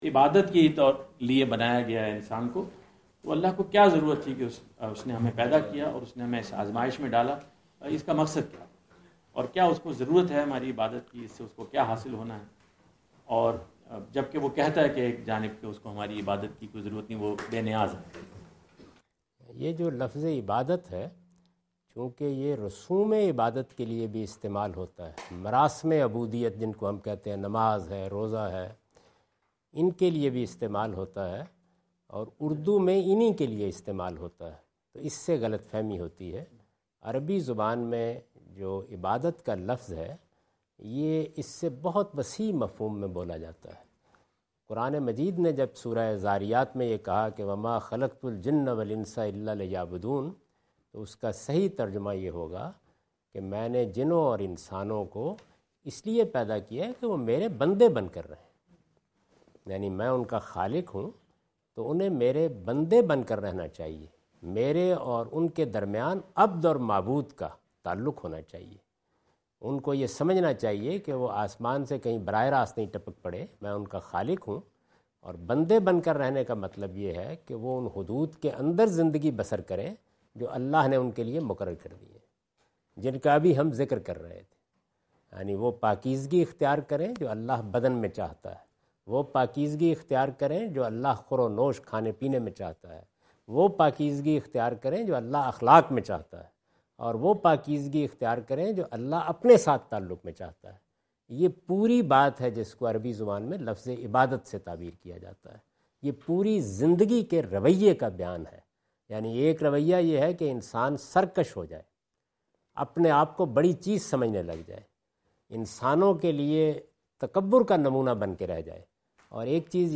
Category: English Subtitled / Questions_Answers /